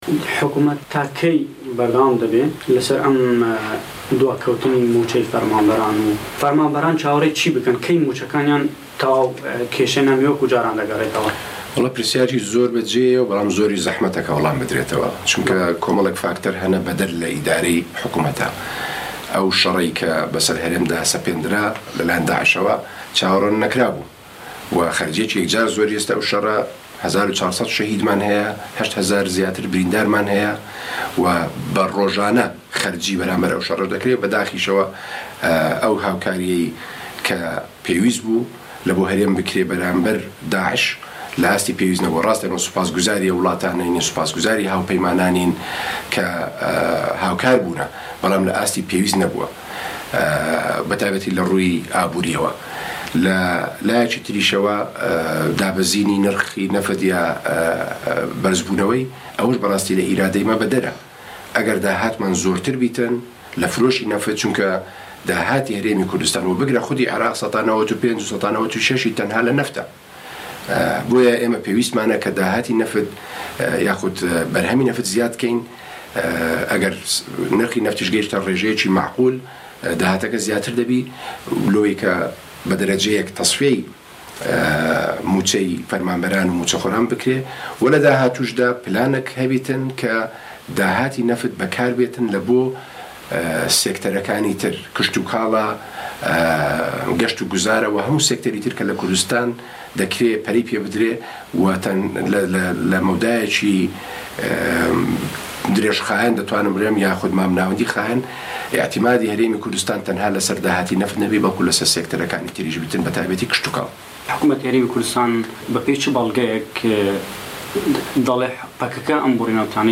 سه‌فین دزه‌یی وته‌بێژی حكومه‌تی هه‌رێمی كوردستان له‌ وتووێژێكدا له‌گه‌ڵ به‌شی كوردی ده‌نگی ئه‌مه‌ریكا باسی قه‌یرانی دارایی هه‌رێمی كوردستان ده‌كات و پێی وایه‌ زیاتر له‌ 95% داهاتی هه‌رێمی كوردستان له‌سه‌ر نه‌وته‌ و نه‌وتیش ئێستا دابه‌زینی به‌خۆیه‌وه‌ بینیوه‌.